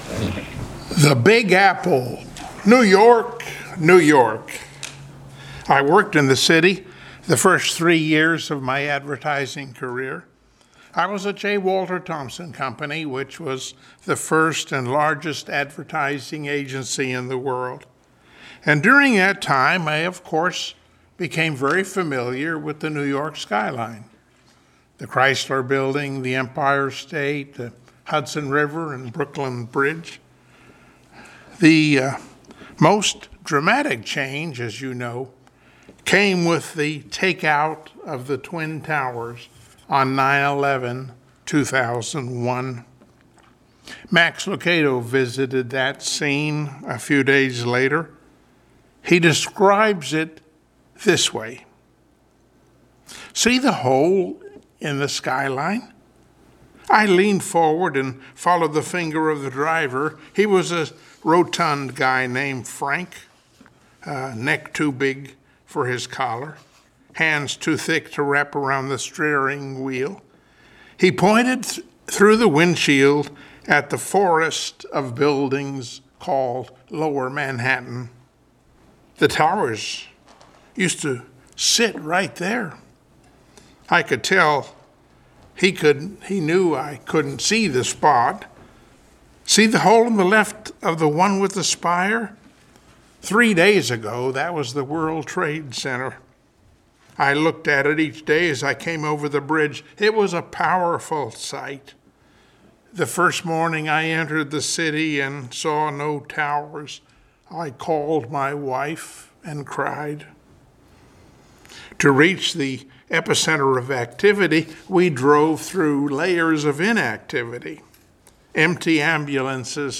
Passage: Psalm 91:1-2 Service Type: Sunday Morning Worship